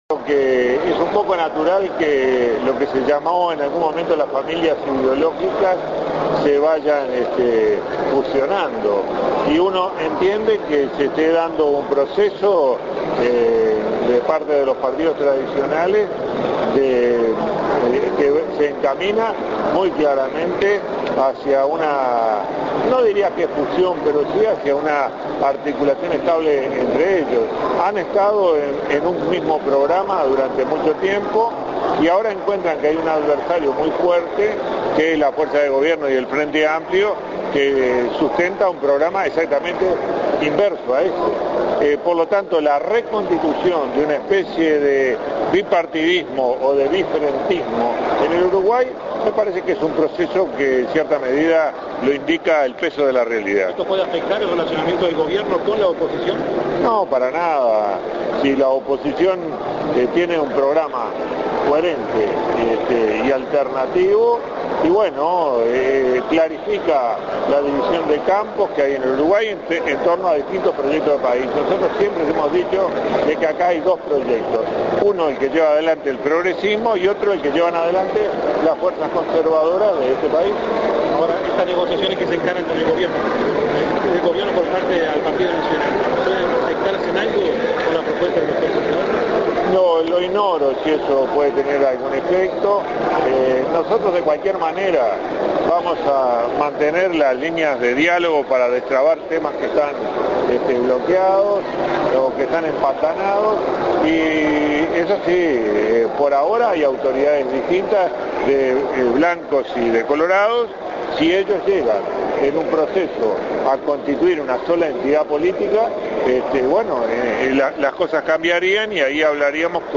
Declaraciones del Director de la Oficina de Planeamiento y Presupuesto, Enrique Rubio.